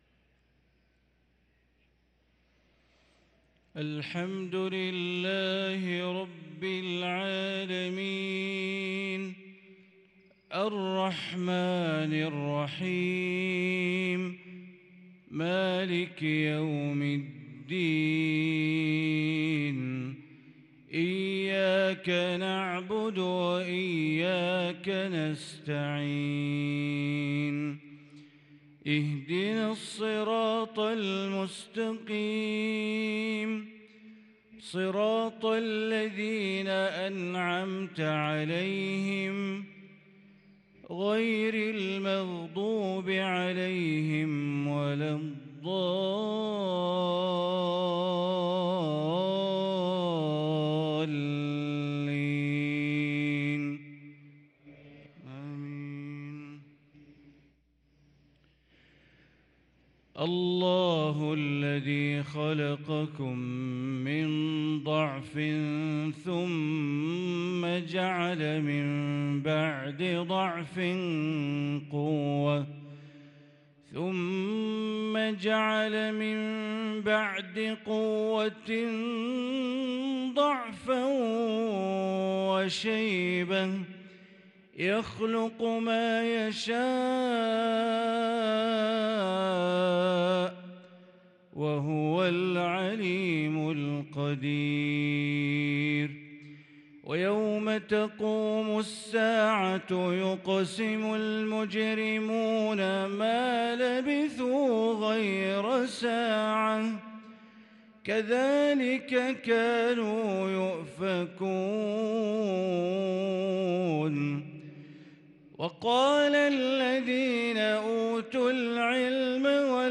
صلاة المغرب للقارئ بندر بليلة 10 ربيع الآخر 1444 هـ
تِلَاوَات الْحَرَمَيْن .